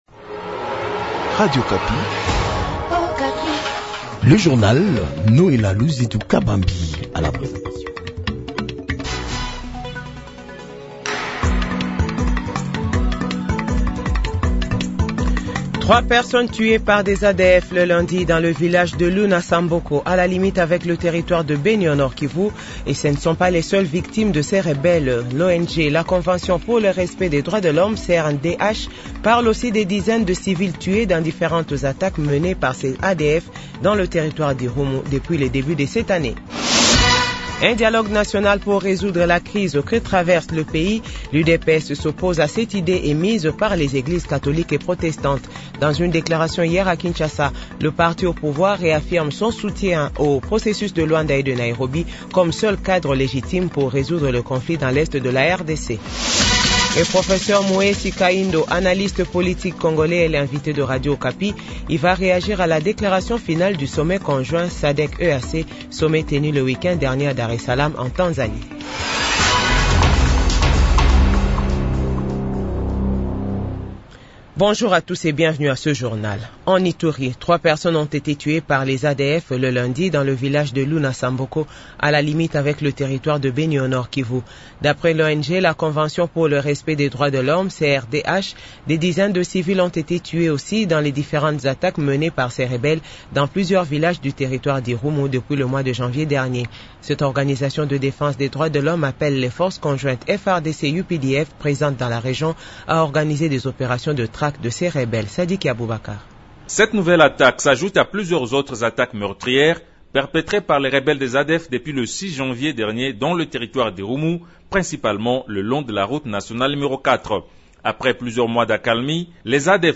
Journal 12h